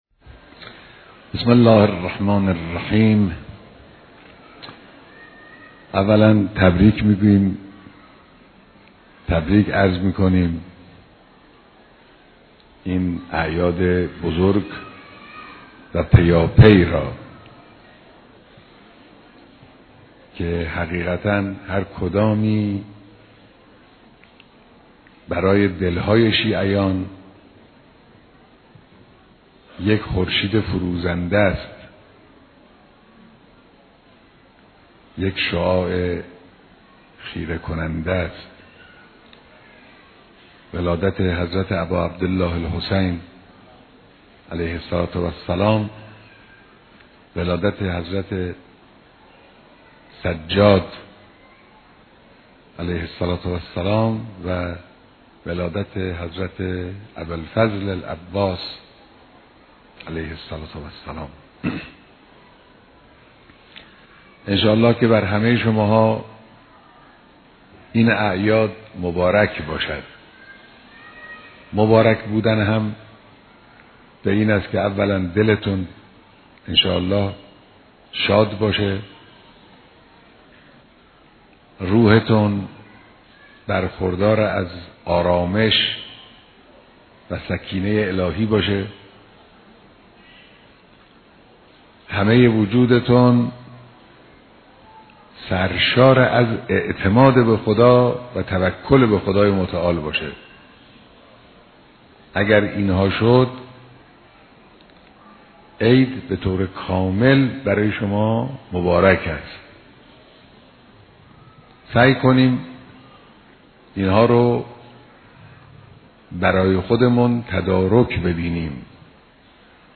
ديدار اعضای دفتر رهبری و سپاه حفاظت ولى امر